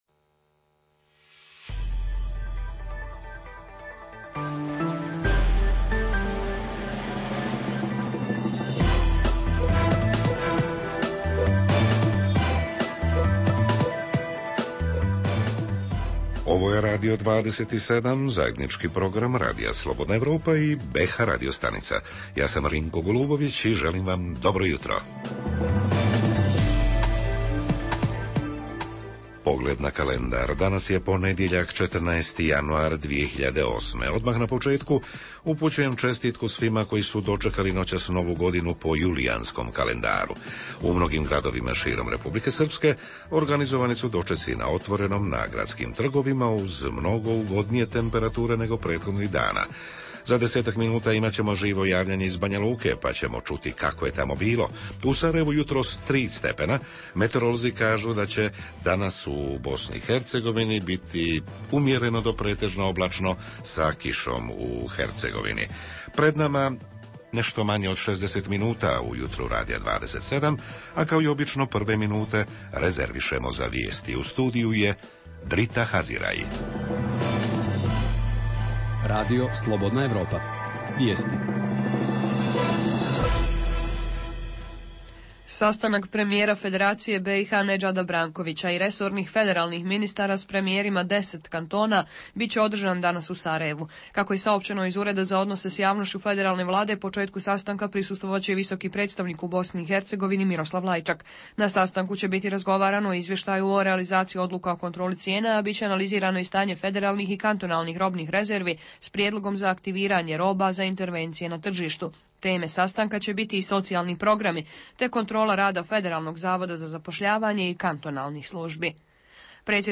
U emisiji Radija 27, osim dnevnih događaja i onog najaktuelnijeg minulog vikenda, tema je i pravoslavna Nova godina, uz javljanje reportera «uživo» sa terena. Redovna rubrika je Raseljeničko-povratnička mini-hronika